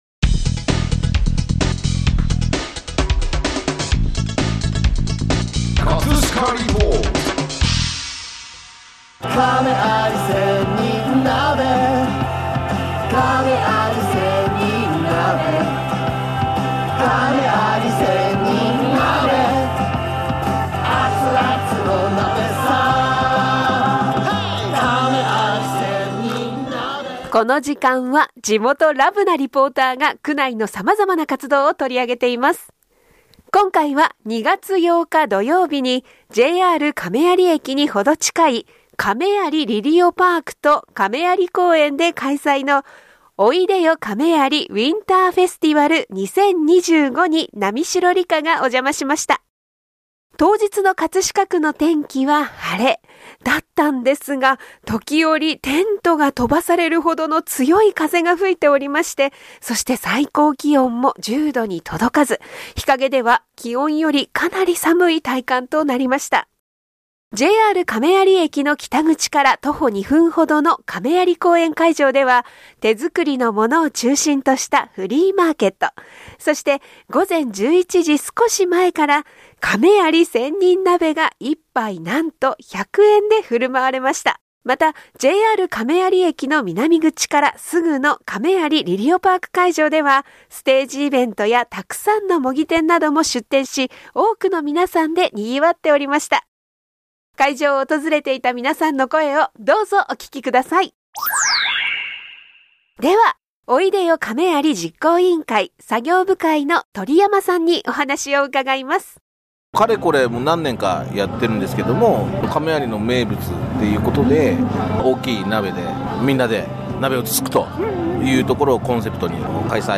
【葛飾リポート】 今回は2月8㈯にJR亀有駅にほど近い亀有リリオパークと亀有公園で開催の「おいでよ亀有Win…
また、JR亀有駅の南口からすぐの亀有リリオパーク会場では、ステージイベントや沢山の模擬店なども出店し、多くの皆さんで賑わっていました。 会場を訪れていた皆さんの声をどうぞお聞きください！